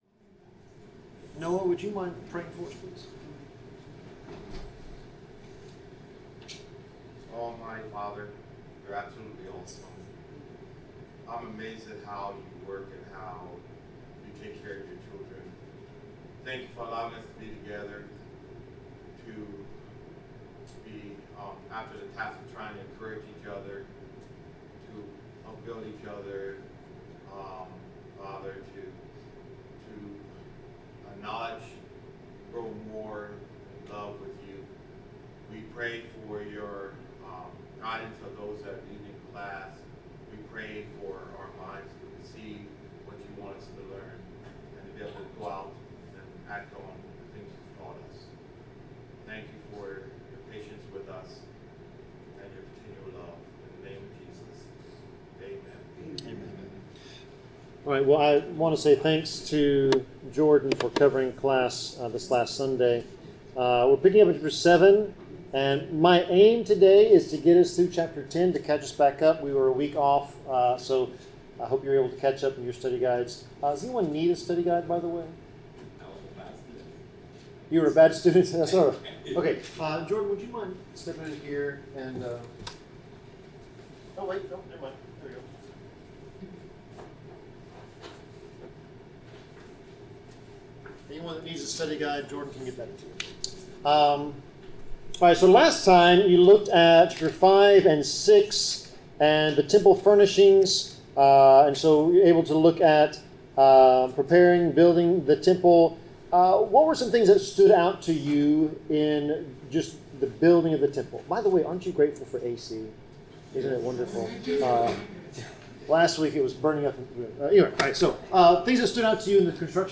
Bible class: 1 Kings 7-9 (The Prayer of Solomon)
Service Type: Bible Class